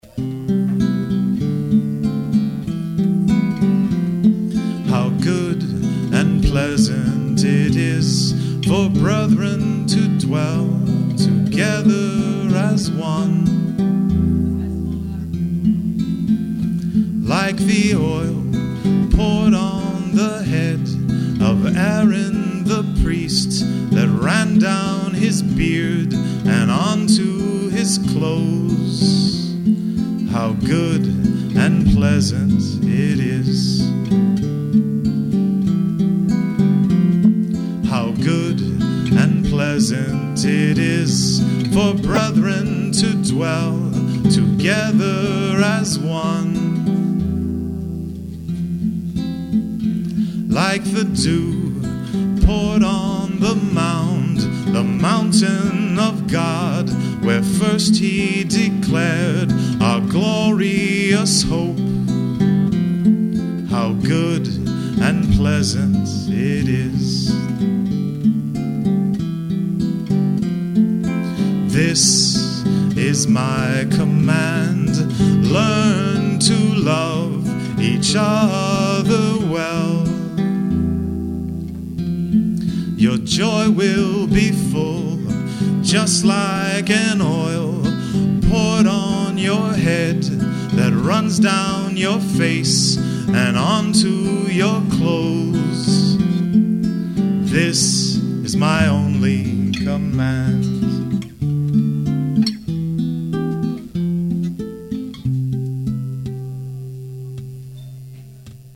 Voice and Guitar- Papa Luigis Restaurant, Pennsville, NJ